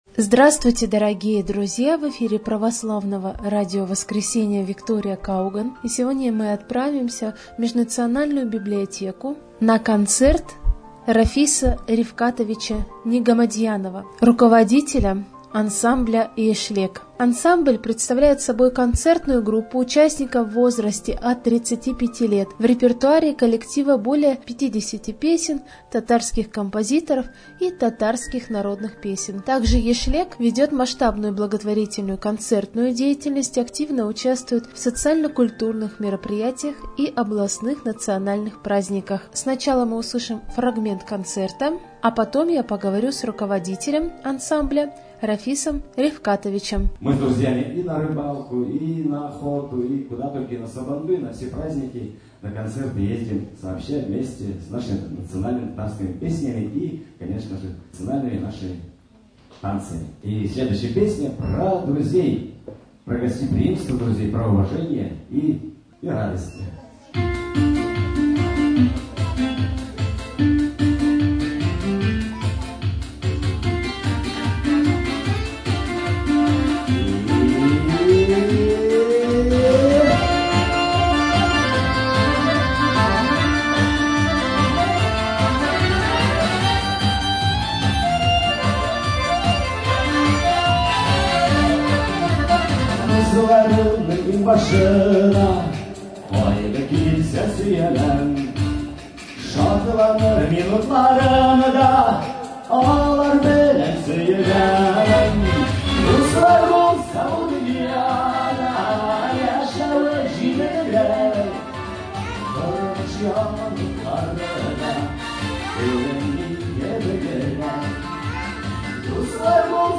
koncert_posvyashchennyj_tatarskoj_kulture.mp3